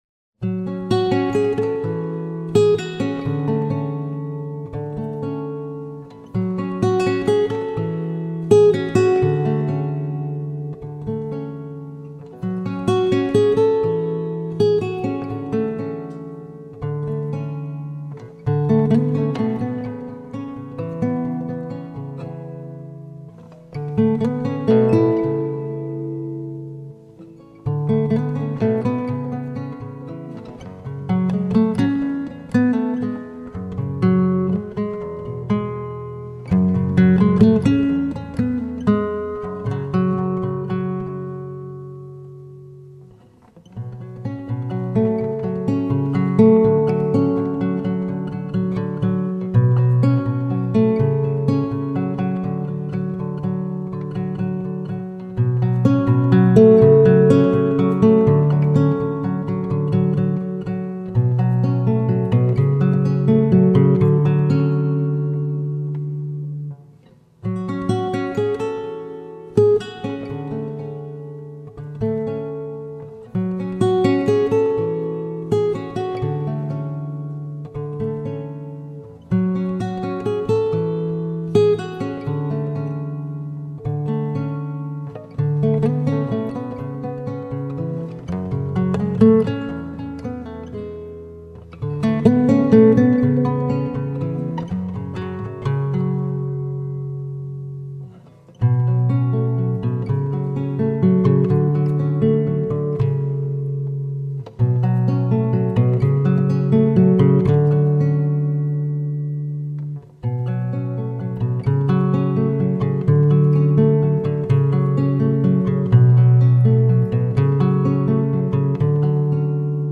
سبک آرامش بخش , گیتار , موسیقی بی کلام
گیتار آرامبخش موسیقی بی کلام آلترنتیو